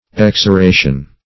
Meaning of exoration. exoration synonyms, pronunciation, spelling and more from Free Dictionary.
Exoration \Ex`o*ra"tion\, n.